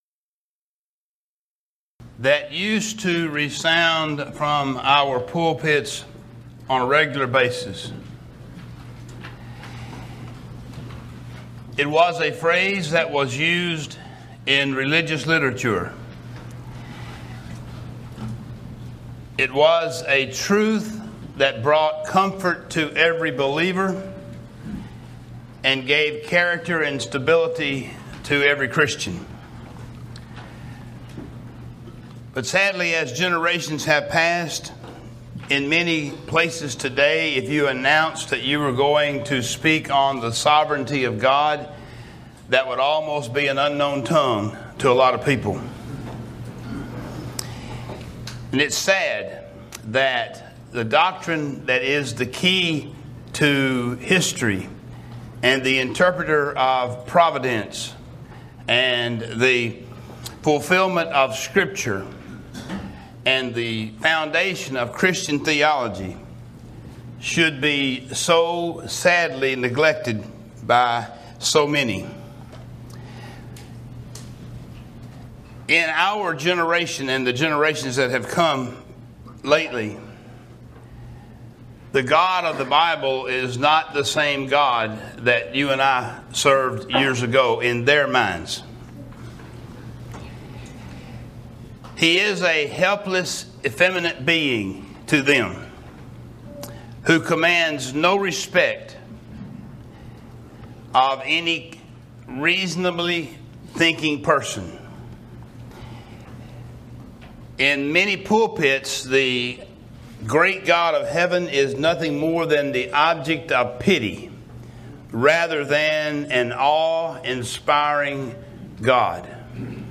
Event: 2016 Focal Point
lecture